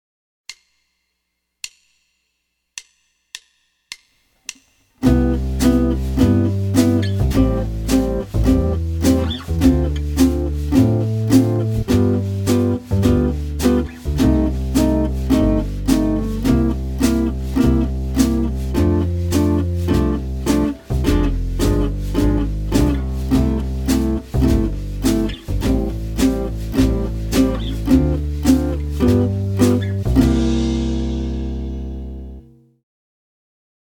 Minor 6 Chord Practice Tune
06-Minor-6-Tune.mp3